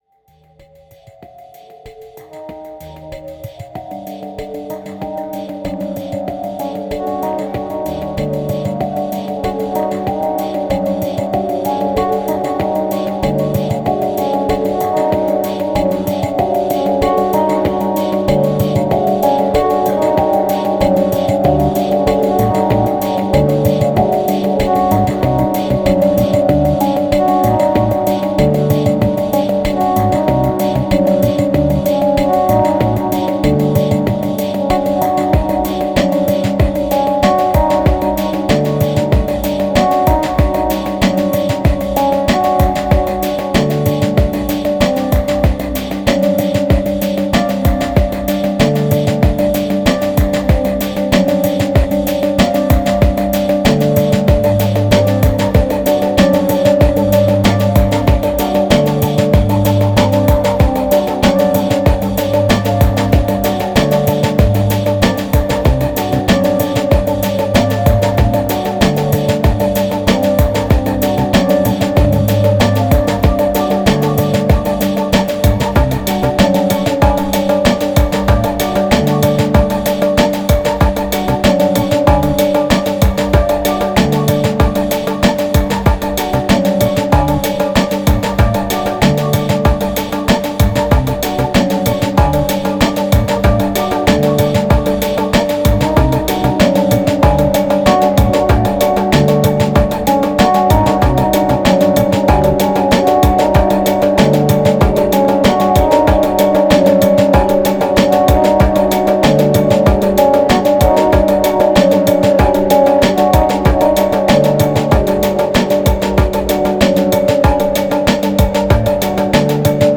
1731📈 - 65%🤔 - 95BPM🔊 - 2014-05-08📅 - 352🌟